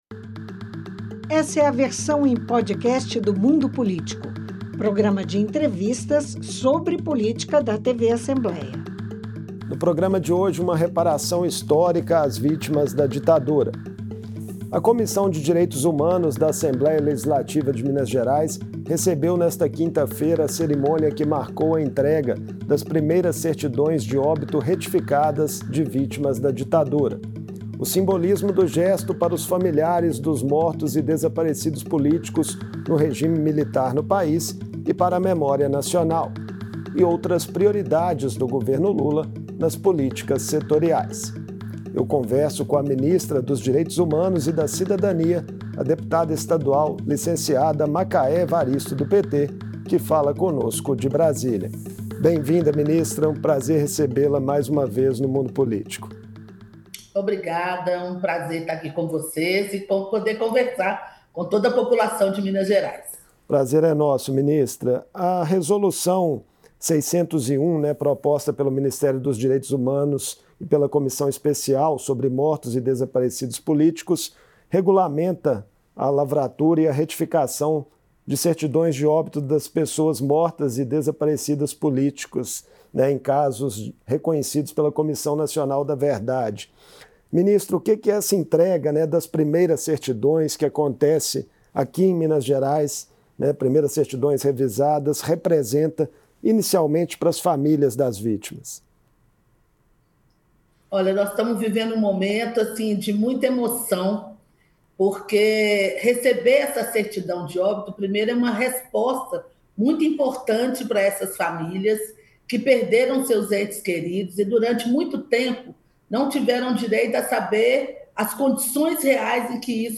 Em cerimônia na Comissão de Direitos Humanos da Assembleia, famílias de mortos e desaparecidos durante a ditadura receberam 63 certidões de óbito retificadas, onde consta a verdadeira causa da morte ou do desaparecimento das vítimas. Em entrevista